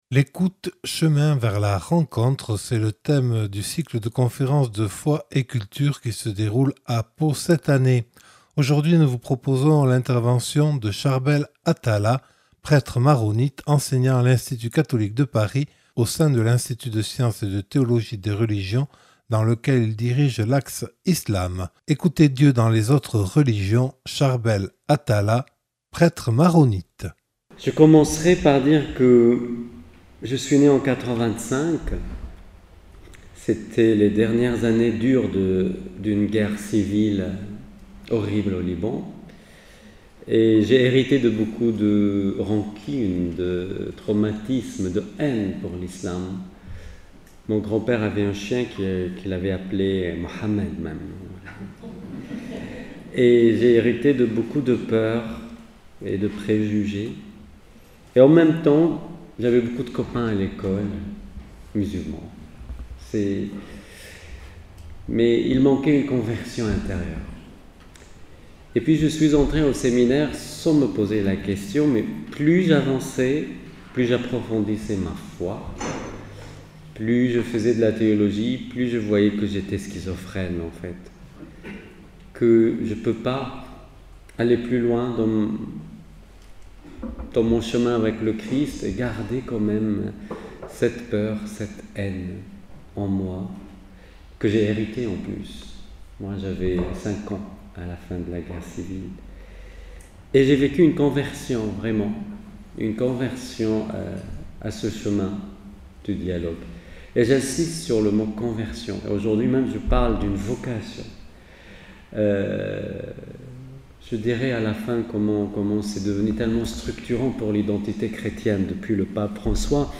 (Enregistré le 21 mars 2025 dans le cadre du cycle « Foi et Culture » à Pau).